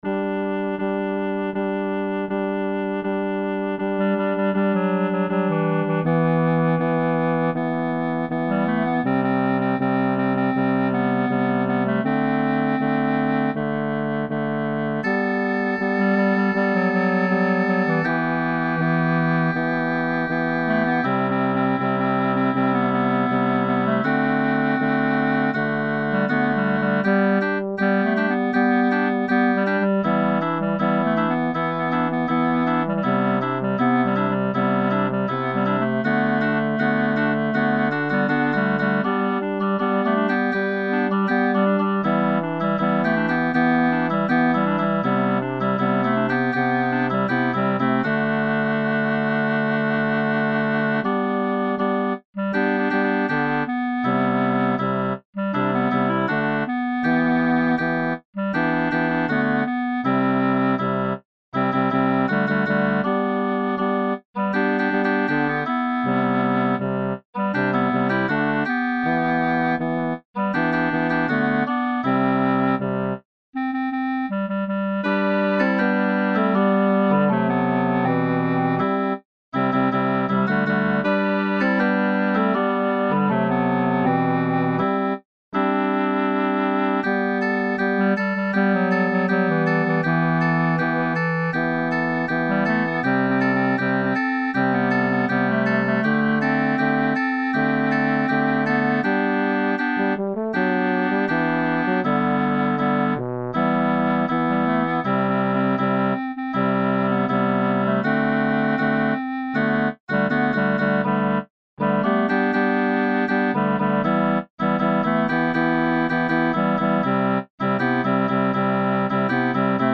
Upper Voices Learning Track Sample